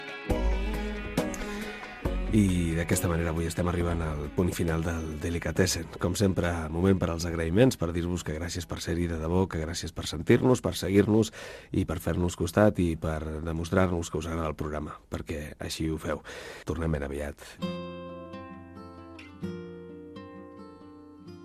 Inici i comiat del programa de música electrònica
Musical